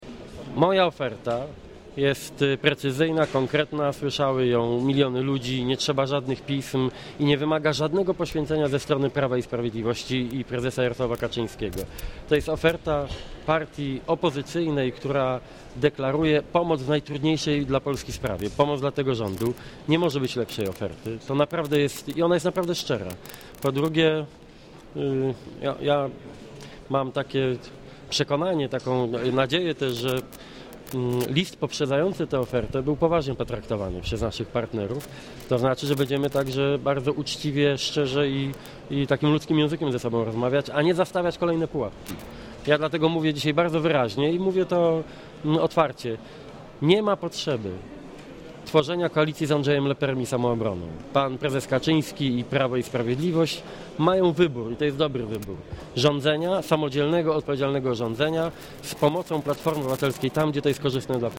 Posłuchaj wypowiedzi Donalda Tuska
Dzisiaj propozycja: "zróbmy w 48 godzin koalicję" jest tak naprawdę propozycją kolejnej awantury - powiedział Tusk na briefingu prasowym.